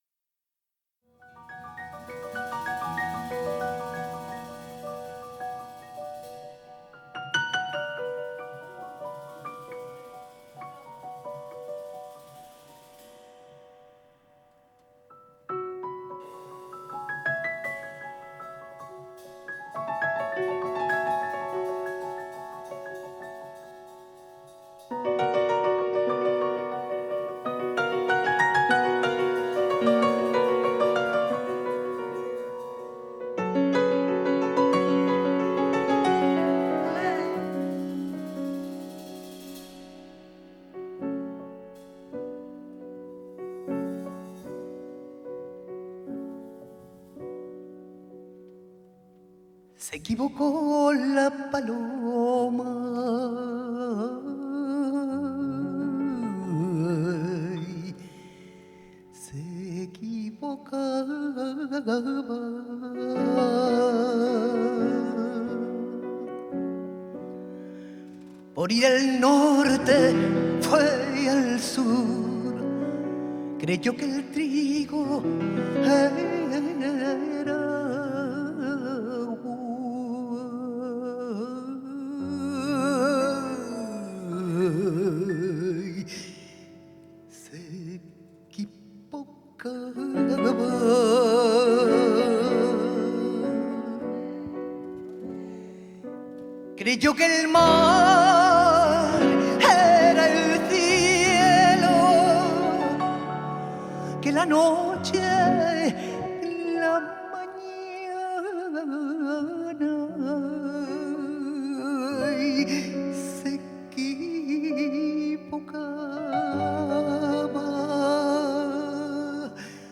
En Directo
piano
guitare
batterie